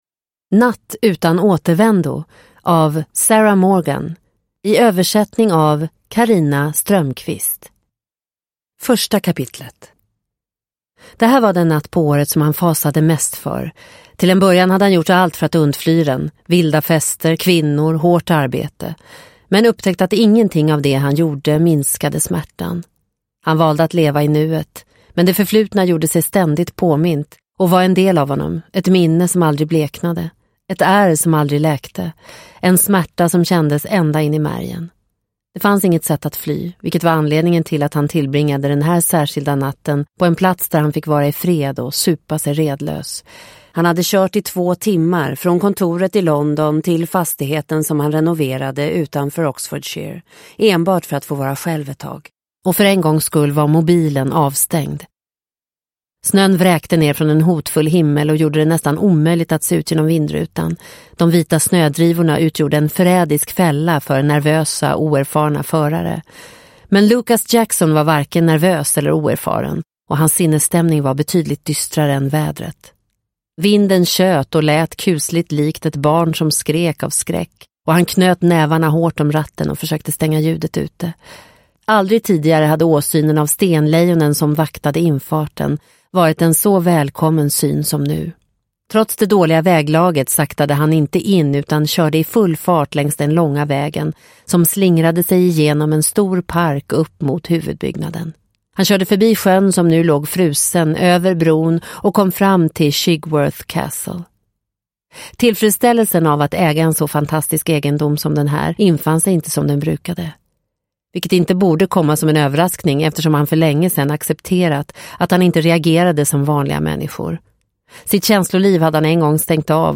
Natt utan återvändo – Ljudbok – Laddas ner